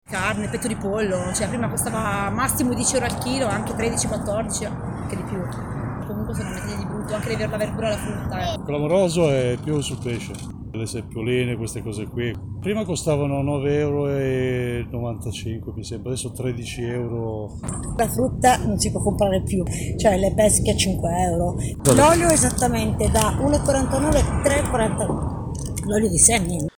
Dopo 100 giorni, le conseguenze economiche della guerra in Ucraina, finora solo nelle analisi degli esperti, pesano sulle tasche degli italiani. Come ci hanno raccontato alcune persone all’uscita di un noto supermercato di Milano.
voci-rincari-1.mp3